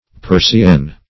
Search Result for " persienne" : The Collaborative International Dictionary of English v.0.48: Persienne \Per`si*enne"\ (p[~e]r`s[i^]*[e^]n"; p[~e]r`sh[i^]*[e^]n"; F. p[~e]r`sy[e^]n"), n. [F., fem. of presien Persian.]